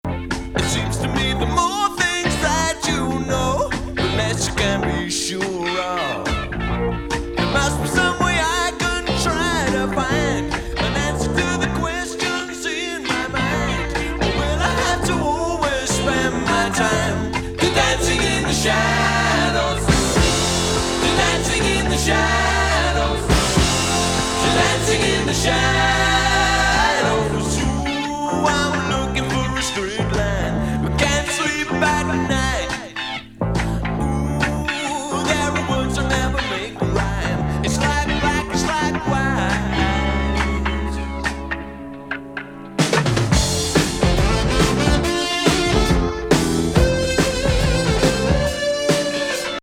ロッキン・レゲなナイス・ナンバー